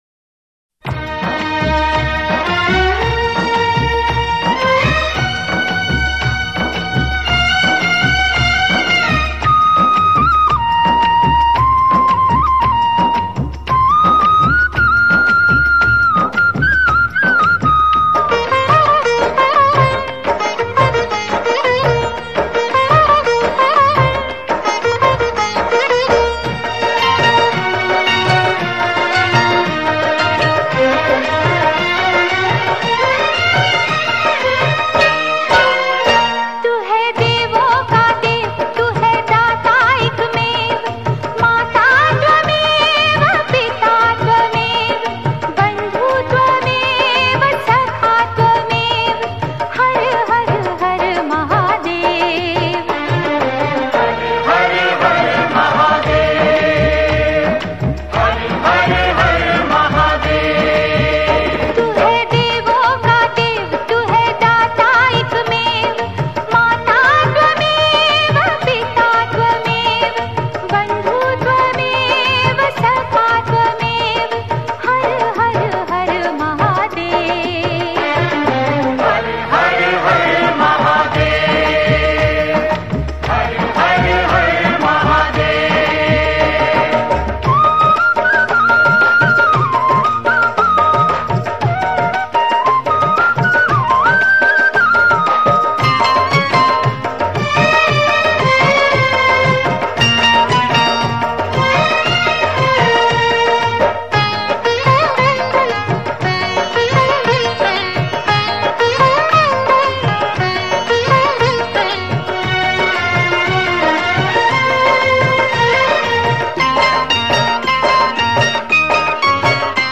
Shiv (Bholenath) Bhajans